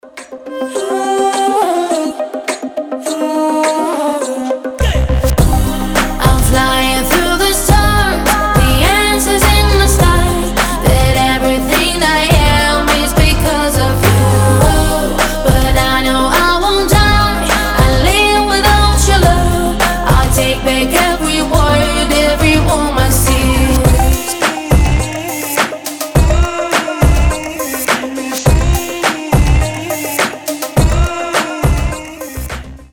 • Качество: 320, Stereo
фолк
красивый женский вокал
Electropop